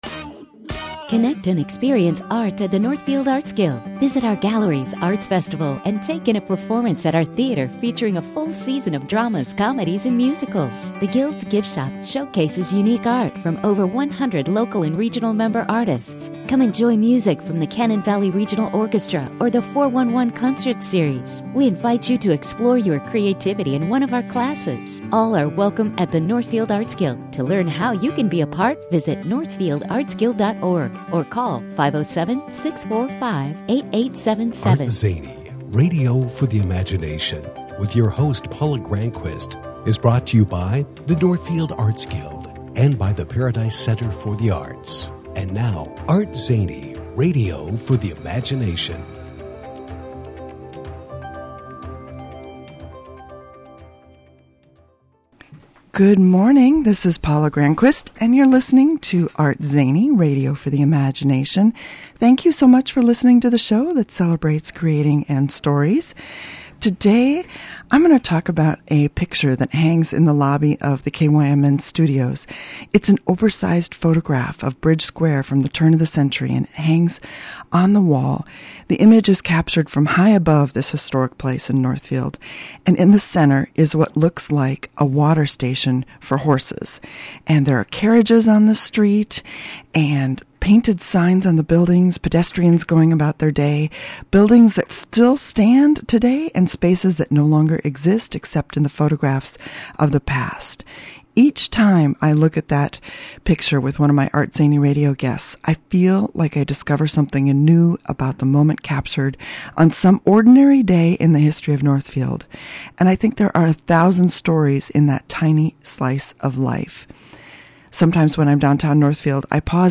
Today in the ArtZany Radio studio